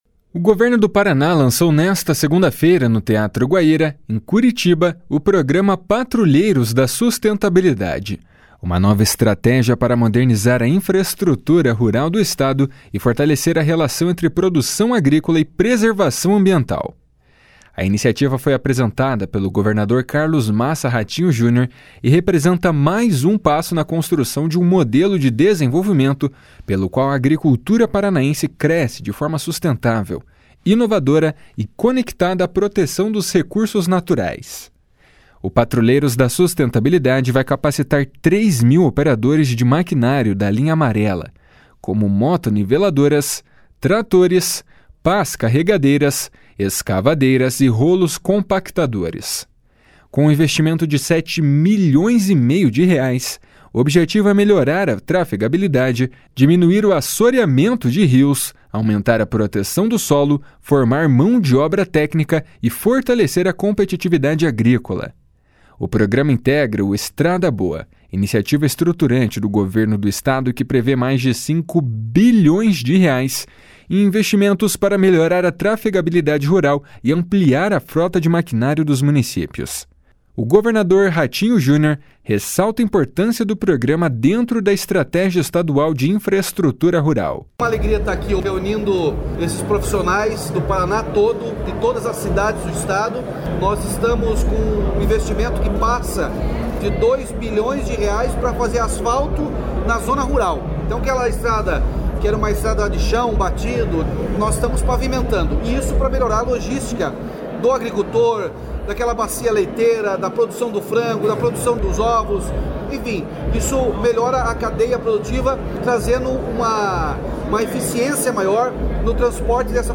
O governador Ratinho Junior ressalta a importância do programa dentro da estratégia estadual de infraestrutura rural.
O secretário da Agricultura e do Abastecimento, Márcio Nunes, destaca que o programa é único no país.
O secretário da Ciência, Tecnologia e Ensino Superior, Aldo Bona, explica o papel das universidades na iniciativa.